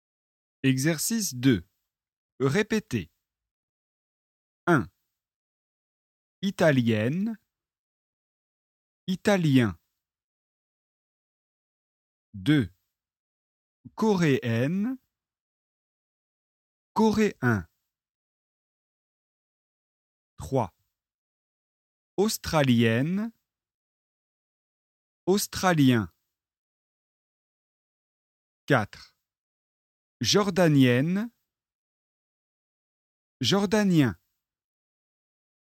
Leçon de phonétique et exercices de prononciation sur les voyelles nasales
⚠ ATTENTION : on ne prononce pas le N final de la voyelle nasale.
🔷 Exercice 1 : répétez